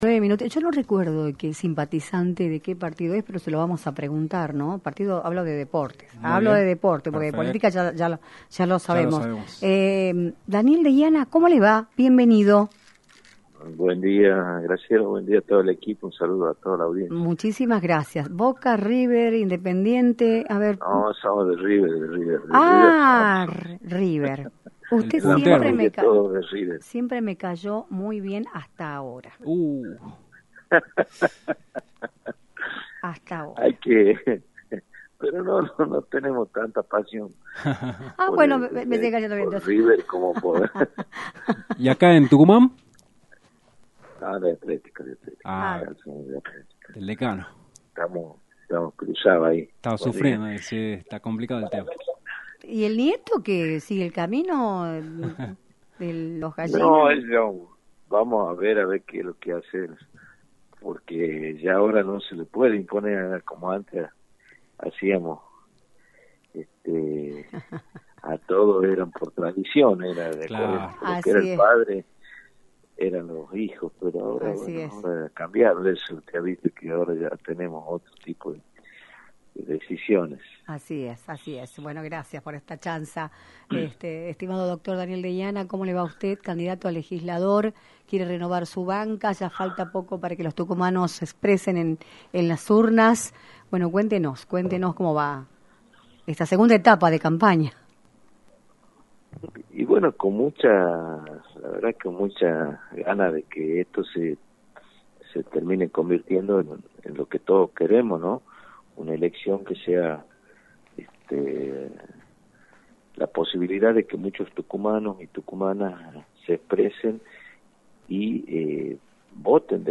El Dr. Daniel Deiana, Legislador y candidato a renovar su banca, abordó en “Libertad de Expresión”, por la 106.9. el panorama político y electoral de la provincia, a 3 semanas de las elecciones establecidas para el próximo 11 de junio.